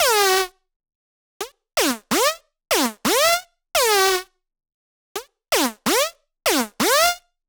VFH1 128BPM Northwood Melody 2.wav